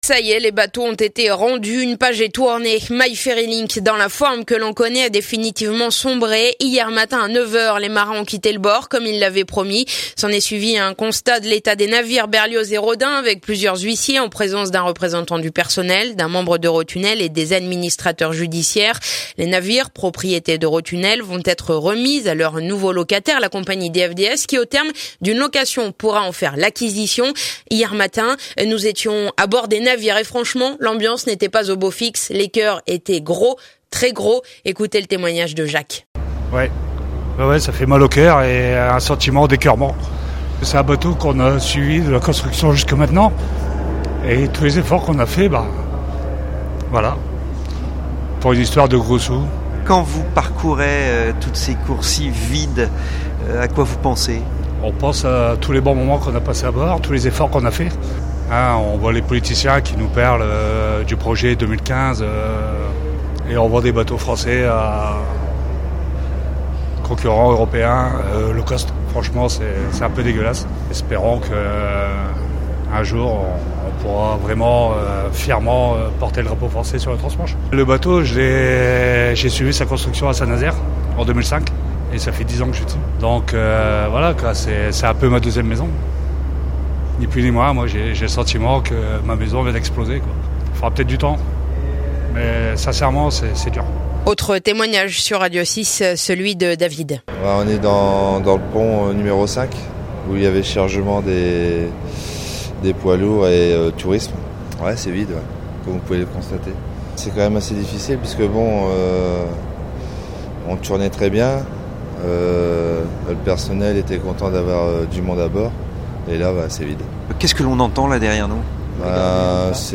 émotions hier sur les navires, reportage